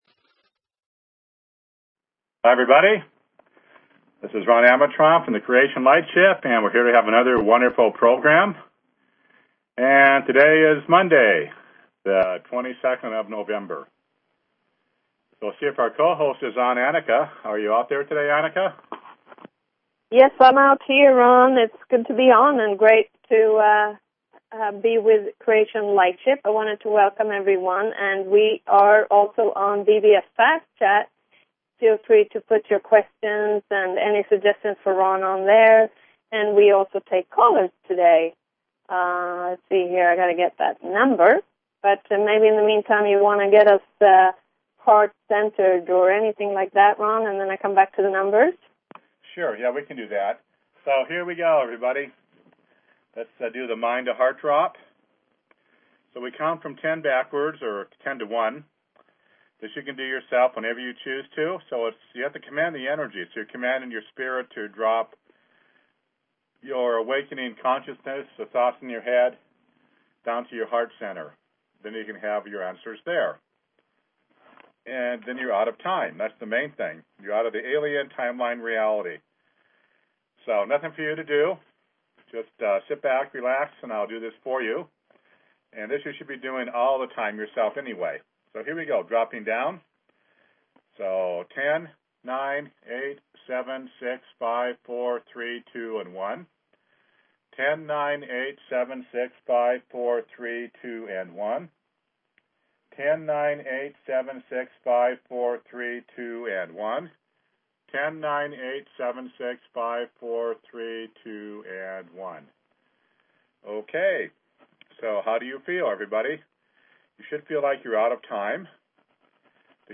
Talk Show Episode, Audio Podcast, Creation_Lightship_Healings and Courtesy of BBS Radio on , show guests , about , categorized as